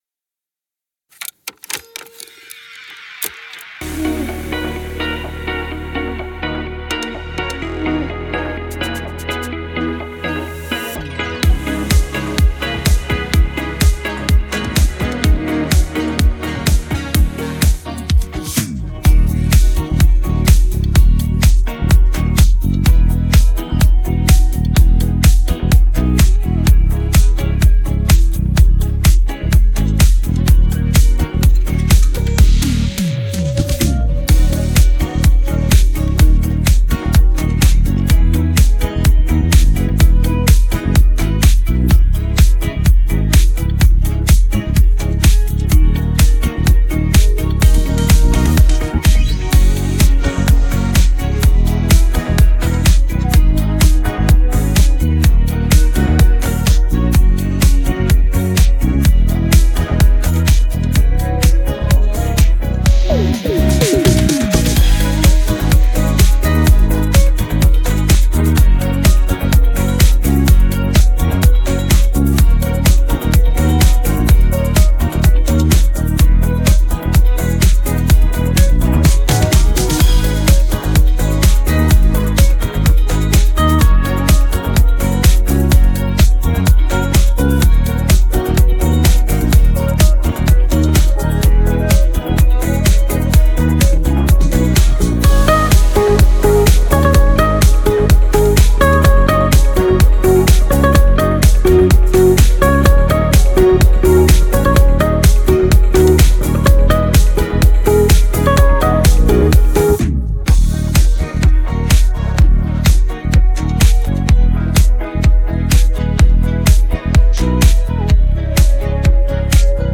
بیت بدون صدا خواننده